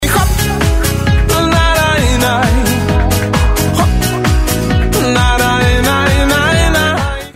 chili khop Meme Sound Effect